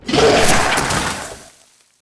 死亡倒地zth070522.wav
WAV · 87 KB · 單聲道 (1ch)
通用动作/01人物/02普通动作类/死亡倒地zth070522.wav